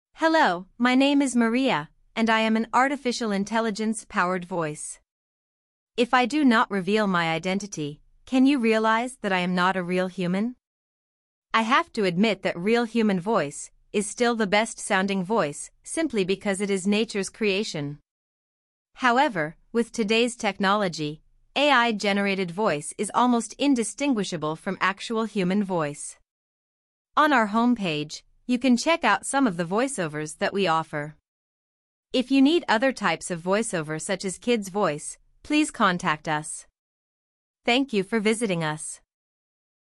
voiceover
Voiceover - Female